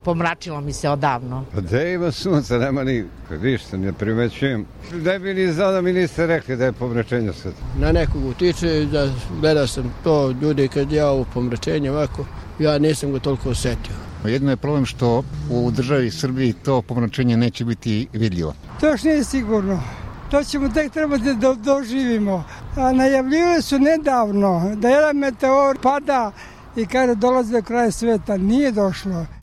anketa